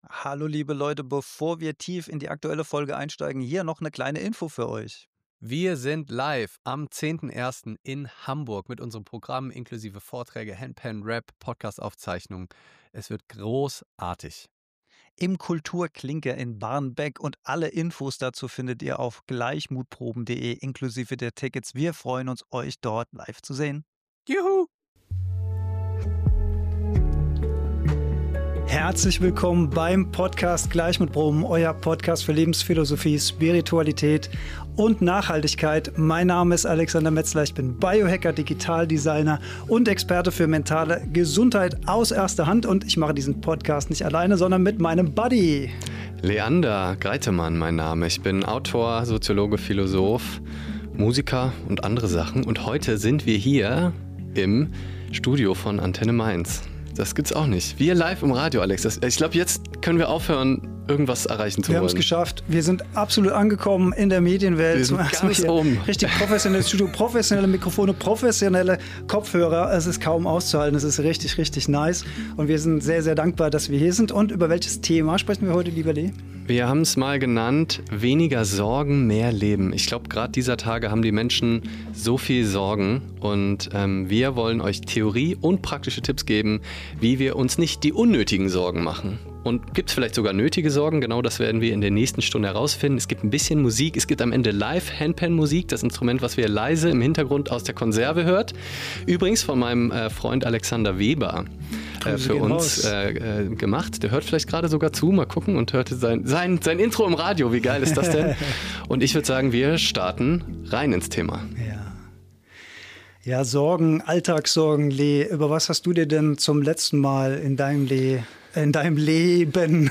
Weniger Sorgen – mehr Leben (Live Radio Antenne Mainz) ~ GLEICHMUTPROBEN | Lebensphilosophie, Spiritualität und Nachhaltigkeit Podcast